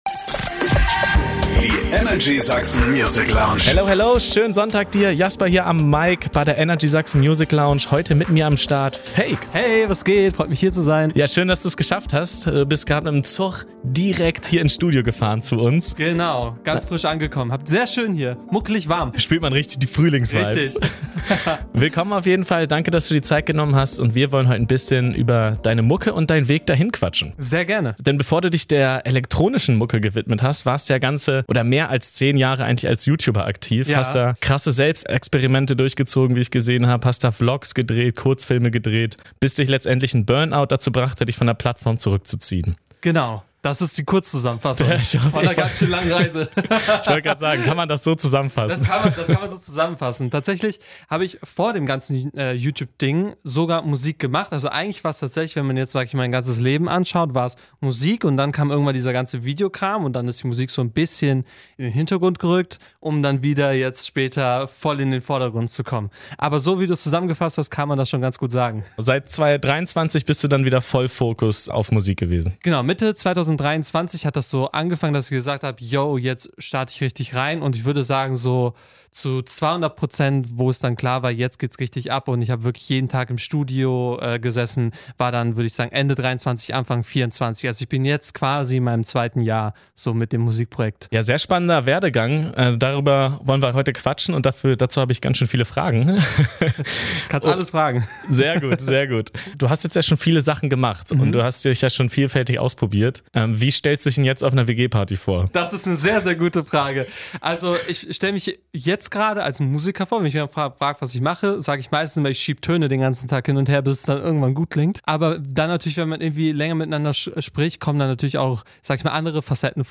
Ein Gespräch voller Einblicke, Inspiration und ehrlicher Worte – unbedingt reinhören!